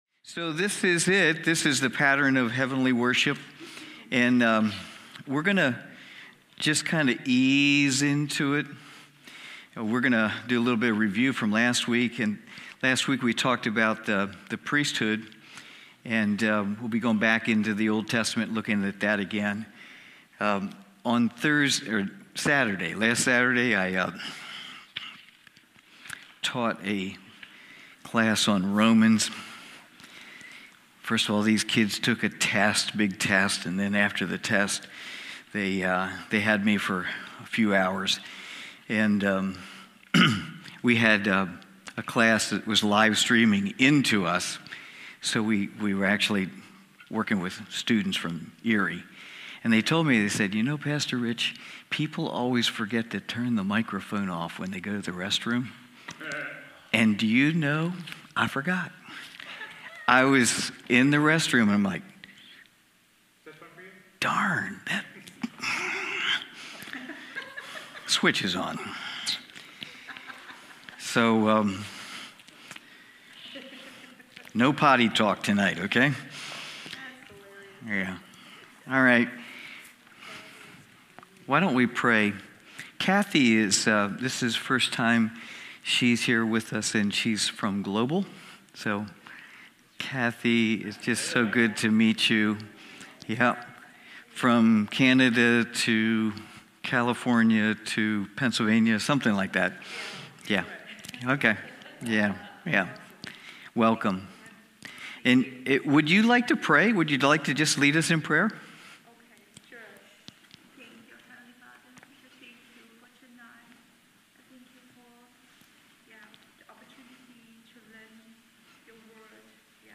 Wednesday evening Bible study.